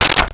Plastic Plate 02
Plastic Plate 02.wav